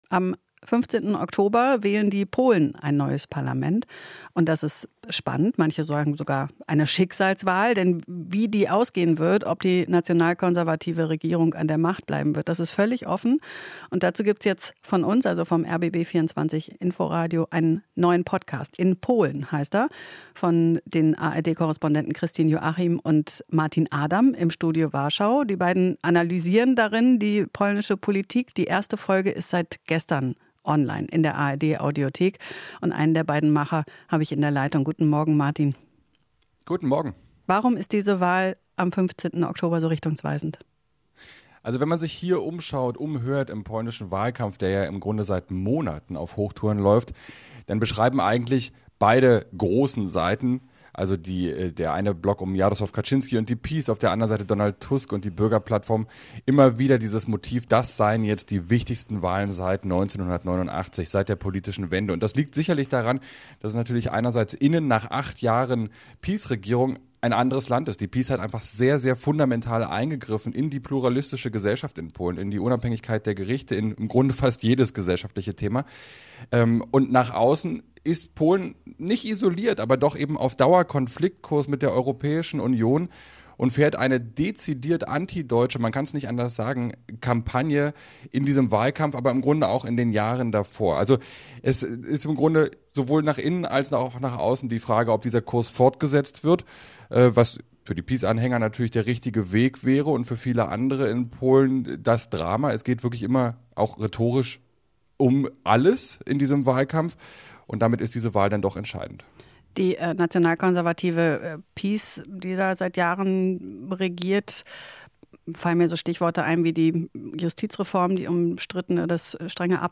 Interview - Neuer Podcast "In Polen": Schicksalswahl im Nachbarland